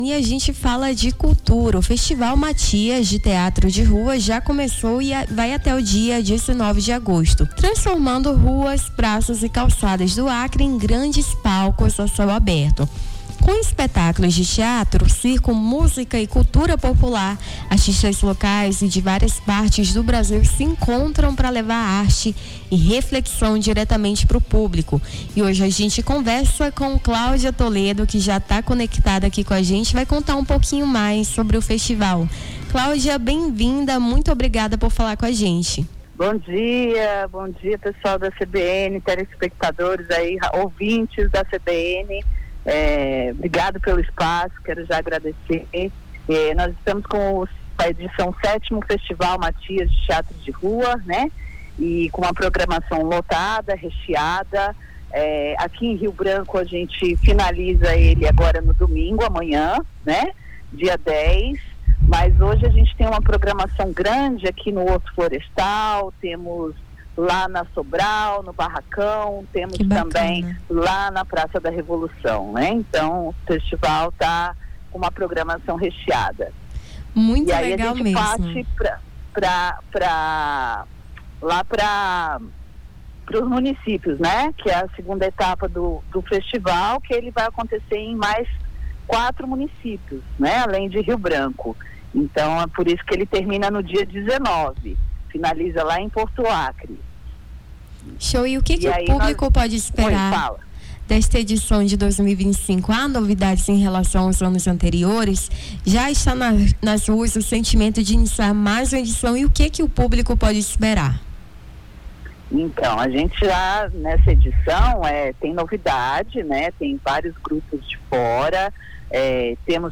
Nome do Artista - CENSURA - ENTR - FESTIVAL MATIAS TEATRO DE RUA - 09.08.2025.mp3